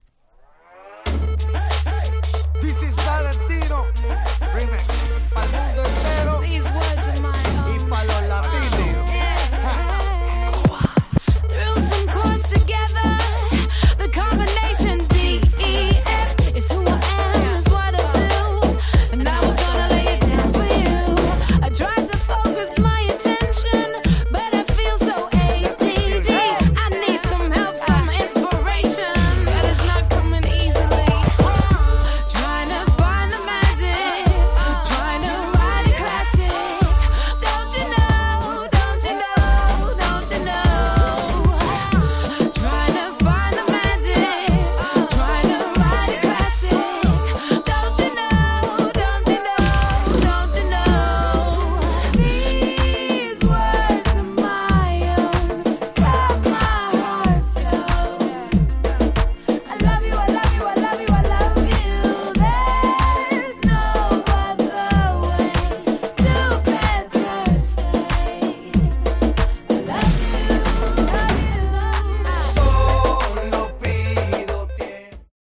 Playするとフロアが何だか温かい雰囲気になるって言うか、すごく重宝しました！